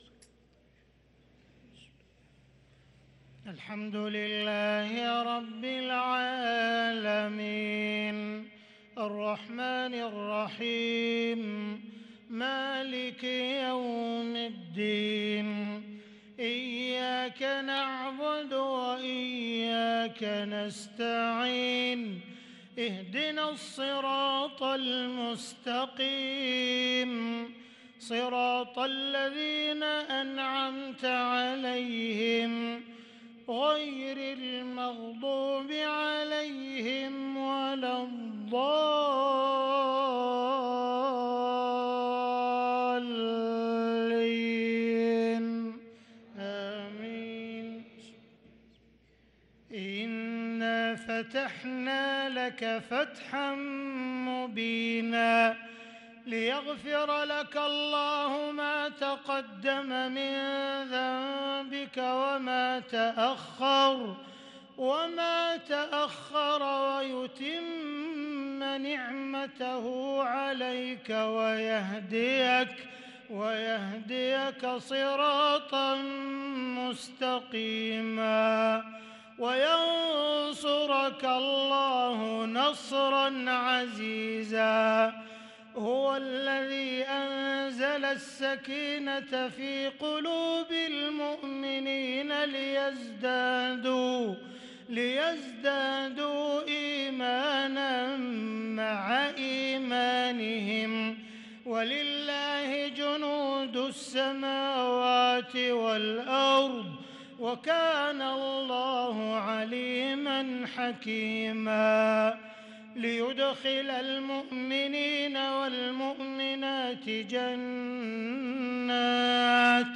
صلاة العشاء للقارئ عبدالرحمن السديس 5 ربيع الآخر 1444 هـ
تِلَاوَات الْحَرَمَيْن .